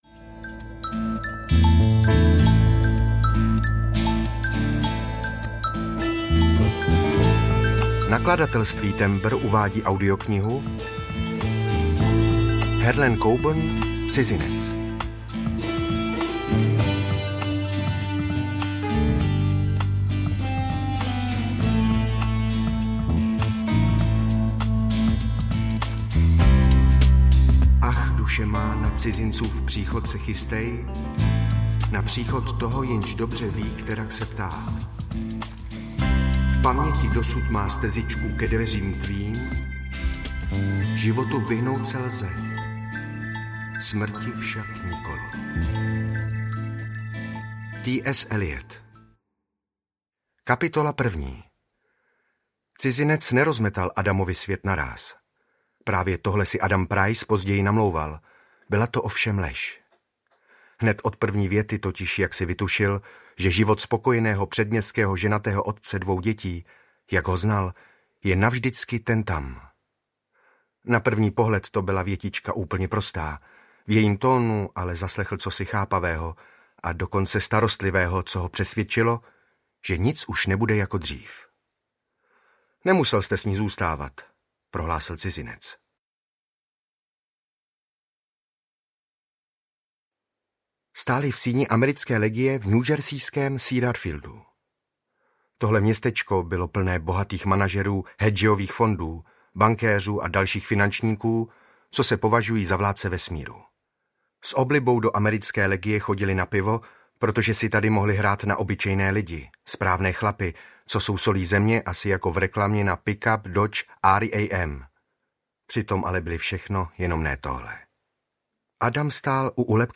Cizinec audiokniha
Ukázka z knihy